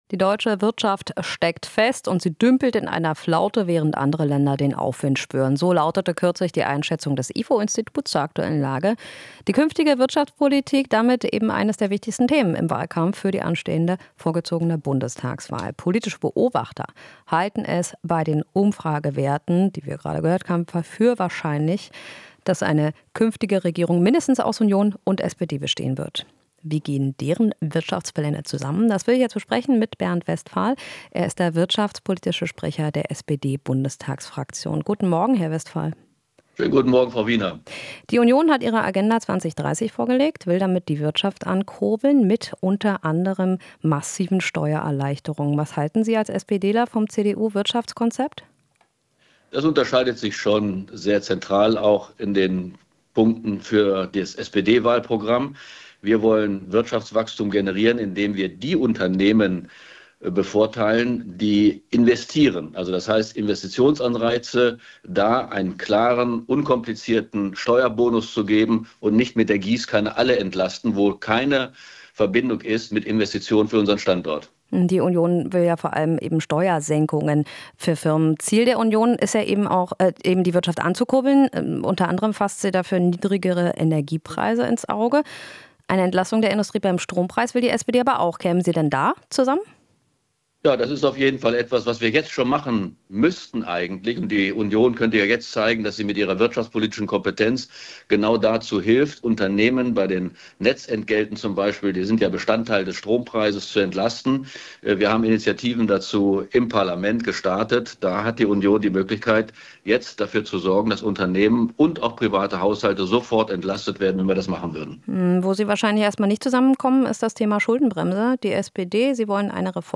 Interview - Westphal (SPD): Investierende Unternehmen fördern